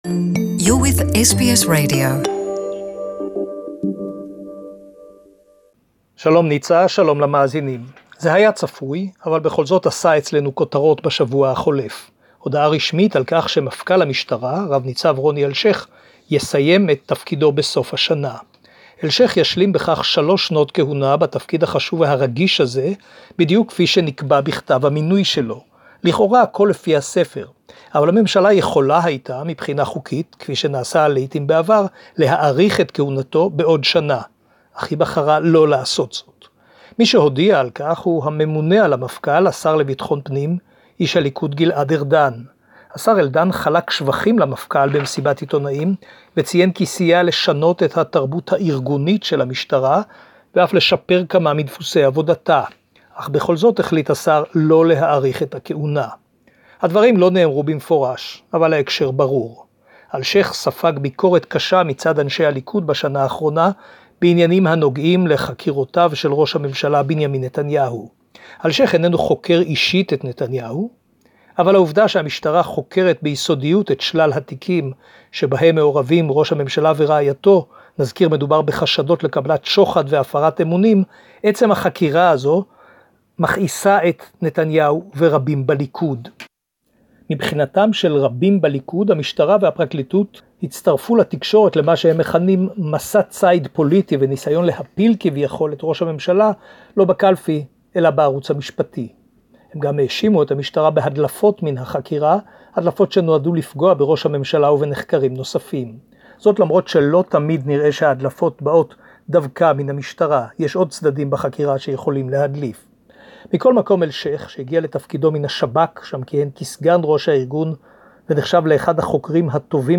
Don't miss the latest report from Jerusalem in Hebrew